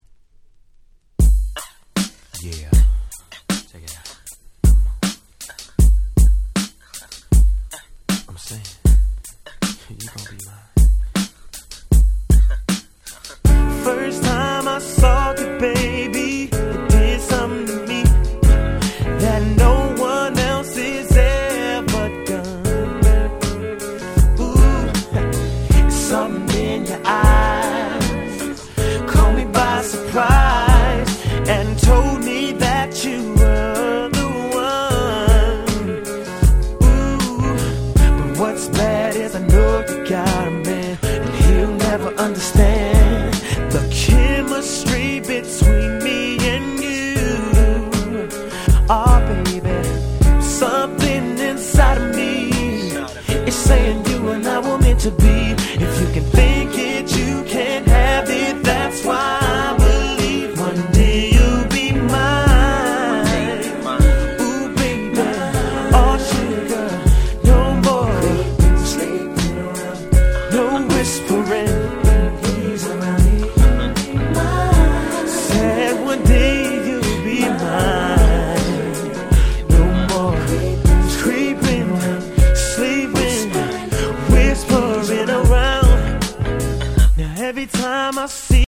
97' Super Hit R&B !!